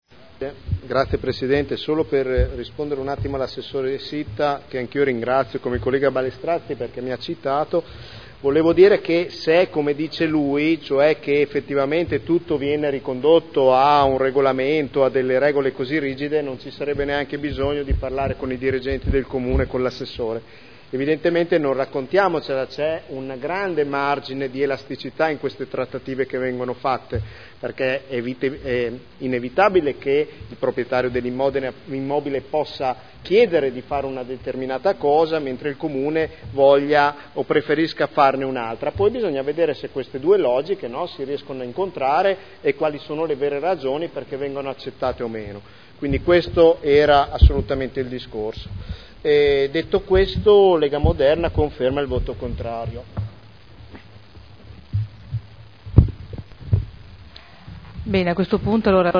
Seduta del 09/01/2012. Dichiarazione di voto su delibera: Variante al Piano Operativo Comunale (Poc) e al Regolamento Urbanistico Edilizio (Rue) – Adozione (Commissione consiliare del 20 dicembre 2011)